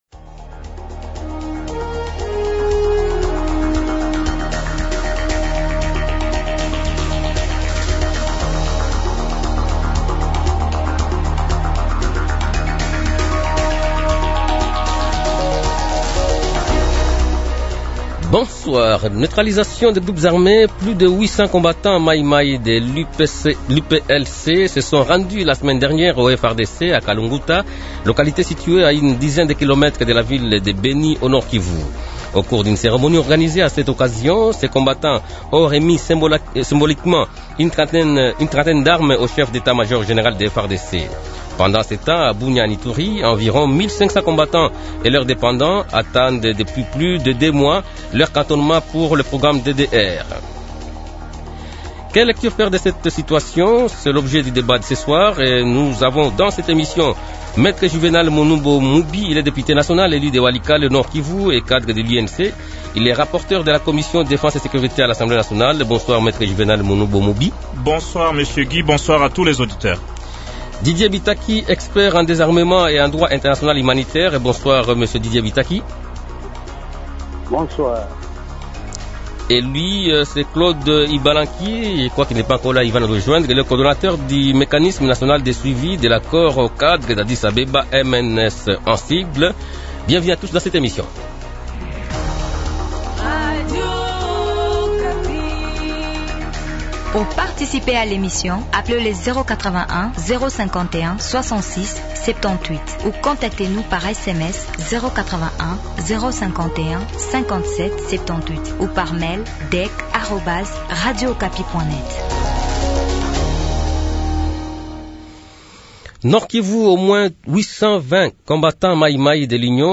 Invités: Me Juvénal Munubo Mubi, Député national élu de Walikale au Nord-Kivu et cadre de l’UNC.